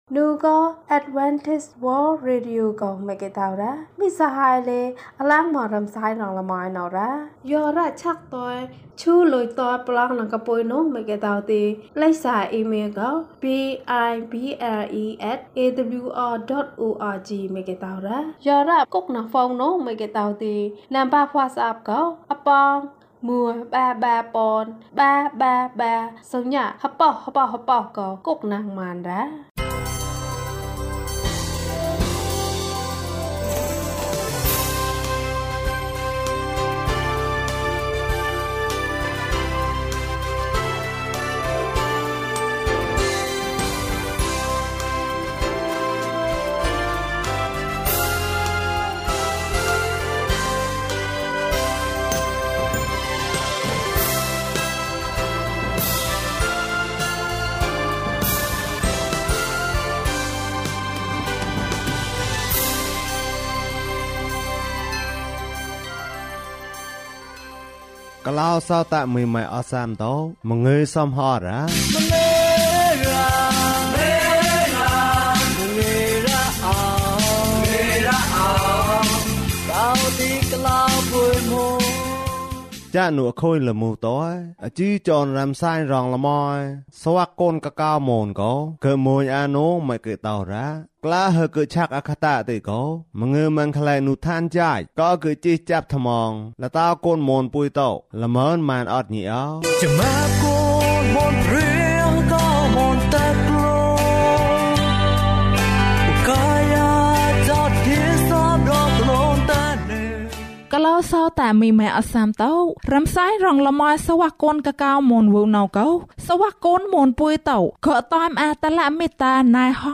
အပြစ်ကင်းဝေးကြပါစေ၊ ကျန်းမာခြင်းအကြောင်းအရာ။ ဓမ္မသီချင်း။ တရားဒေသနာ။